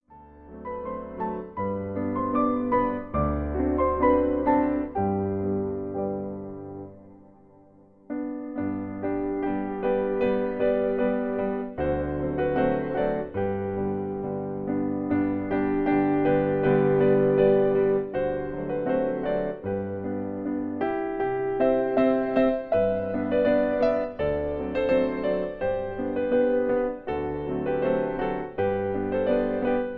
Traditionelles deutsches Volks-/Kinderlied